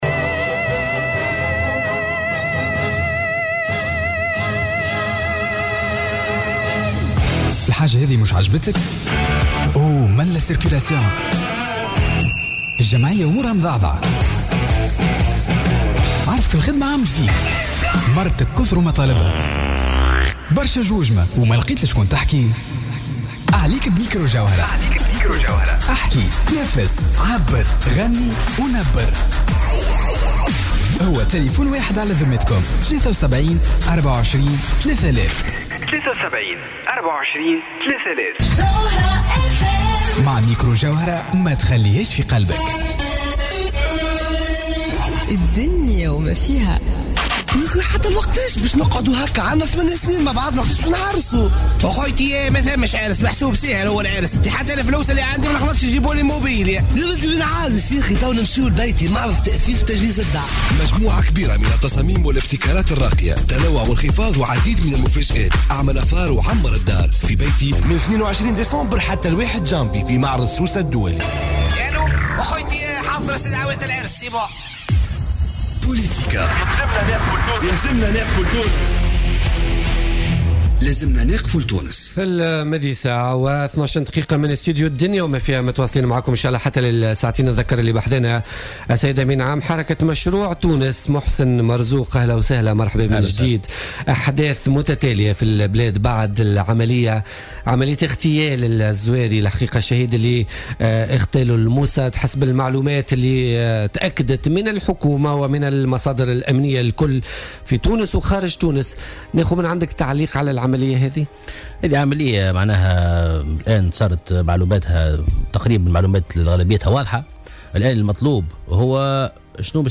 وقال ضيف "بوليتيكا" إنه على تونس في هذه الحالة البحث عن إدانة سياسية على المستوى العربي والدولي لما حصل من استباحة لسيادة تونس.